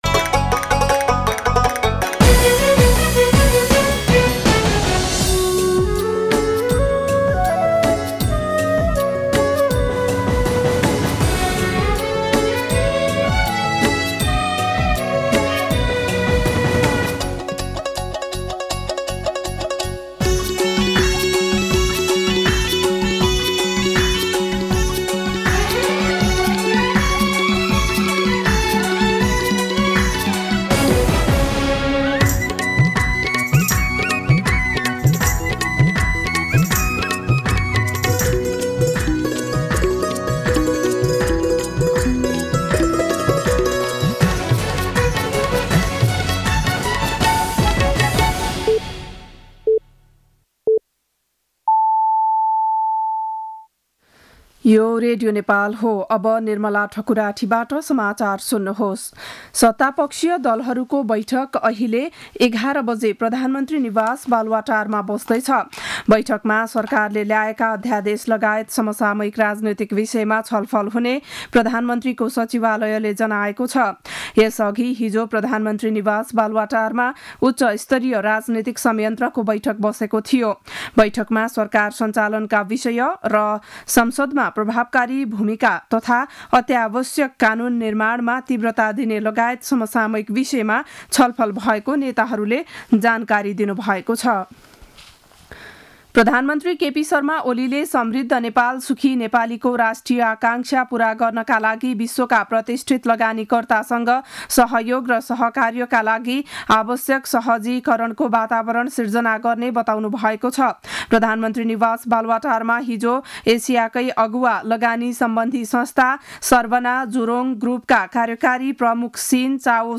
बिहान ११ बजेको नेपाली समाचार : ४ फागुन , २०८१